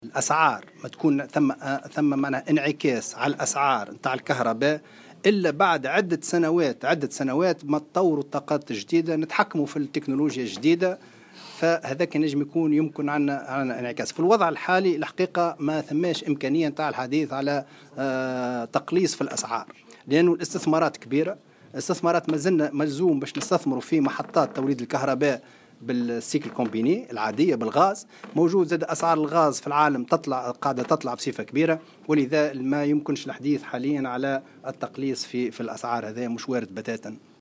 قال وزير الطاقة والمناجم والطاقات المتجددة خالد قدور، اليوم الخميس في تصريح لمراسل الجوهرة اف ام، أن التخفيض في أسعار الكهرباء حاليا غير وارد بتاتا.